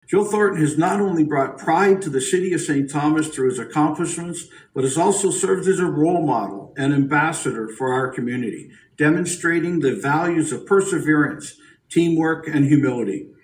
In reading the proclamation at city hall, Preston praised Thornton for his dedication, talent and love of the game.